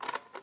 record dn.aiff